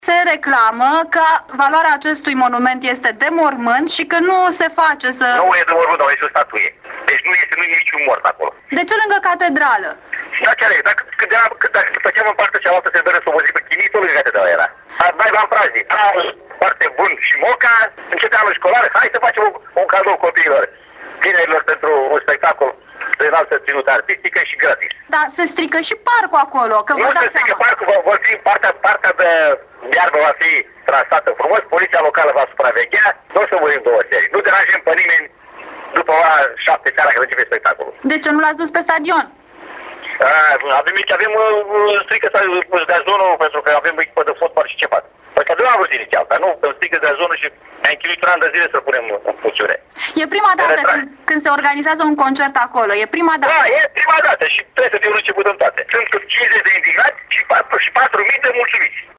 Pentru primarul Alexandru Stoica, autorizarea unor spectacole lângă Catedrala Episcopală e cât se poate de firească şi e o soluţie de a proteja gazonul de pe stadion: